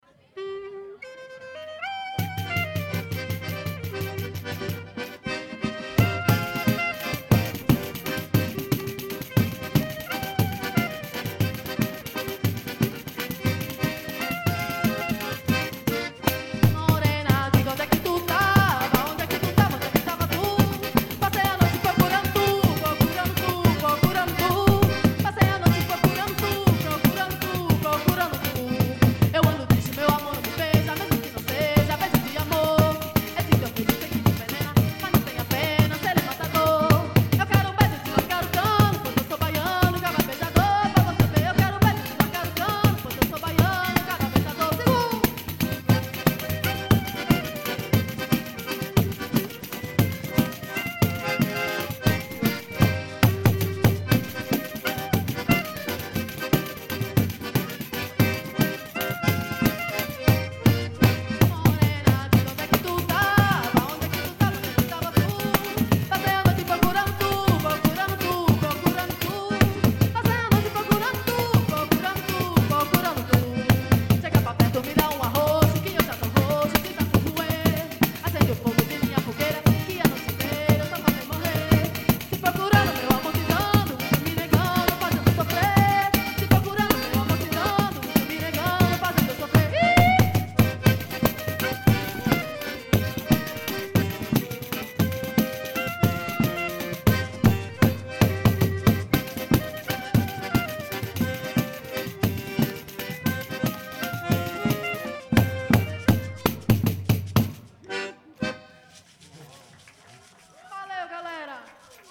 E essas meninas??